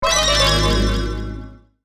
Mobile Game Sound Effects Download.
Power-down-retro-mobile-game-6.mp3